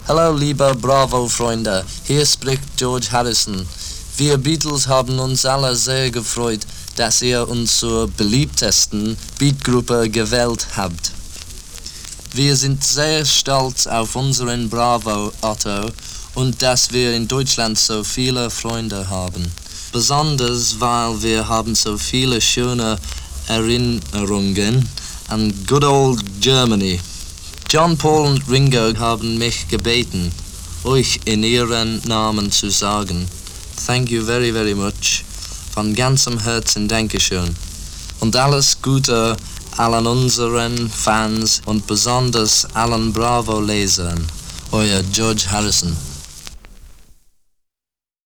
George Harrison made a speech in german for the Bravo Award.
george-harrison-german-speech-1966-bravo-award.mp3